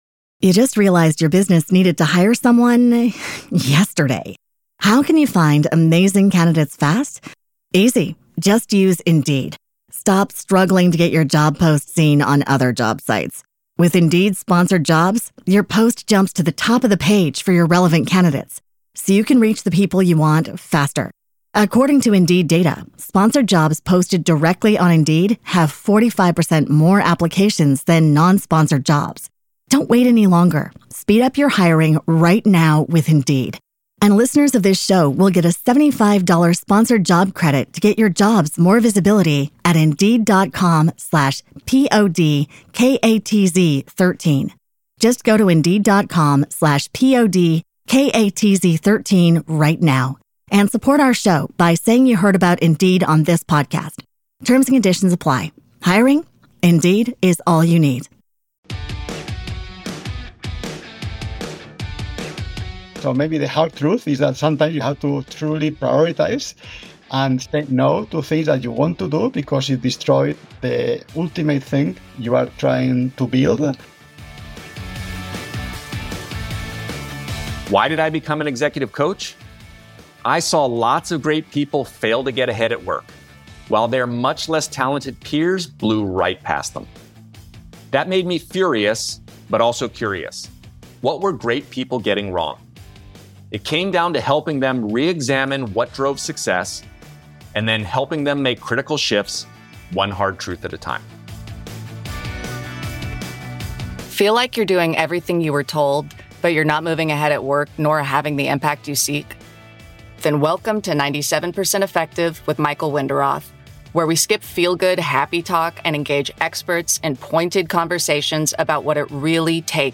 Season #1 focuses on Power and Influence, two widely acknowledged (but poorly understood) forces that can help you rise, lead more effectively and get big things done. Each week we have candid conversations with an academic, coach, or executive, helping you gain new insights to better navigate your work and career.